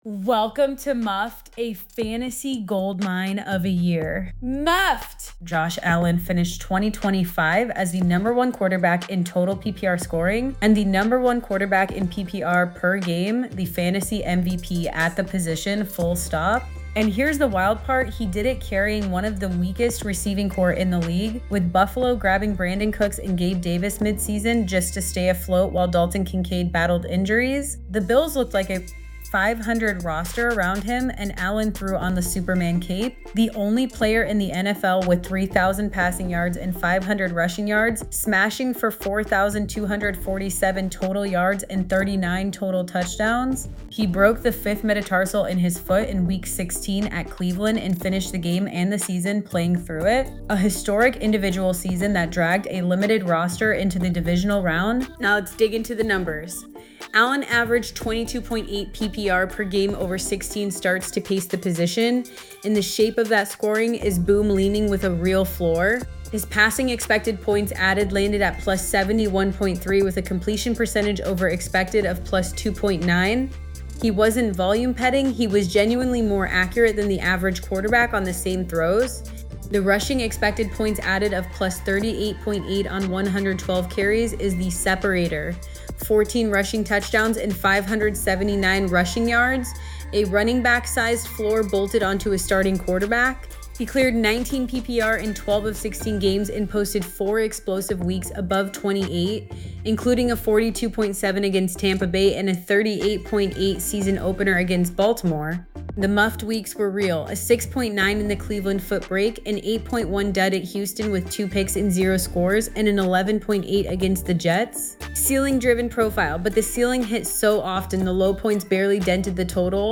Player · 2025 Season ReviewQB · BUF
VoiceHot takes and fillerSmart football friend who watched every snap
Personality, energy, and confidence.